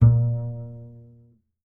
pluck
BS A#2 PI.wav